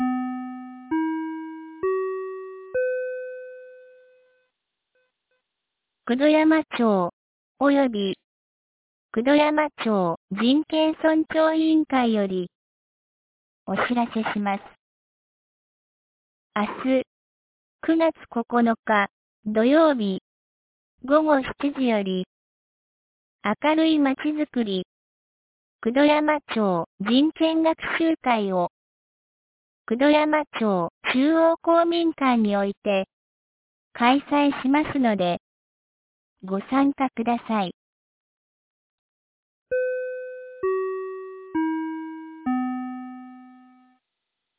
2023年09月08日 16時15分に、九度山町より全地区へ放送がありました。